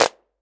Vermona Clap 05.wav